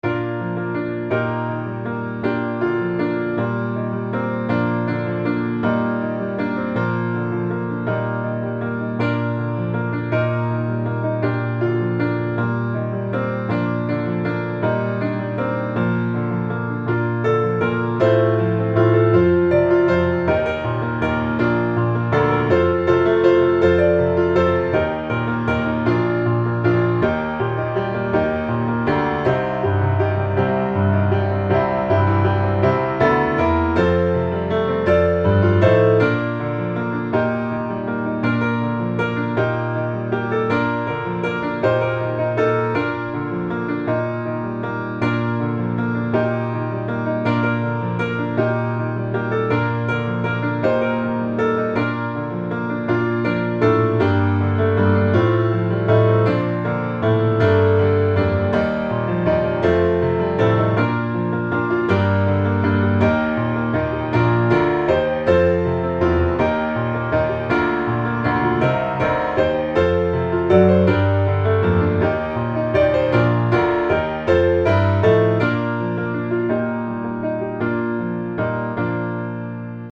Bb Major